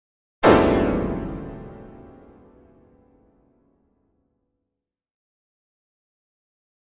Piano Harsh Jarring Chord 2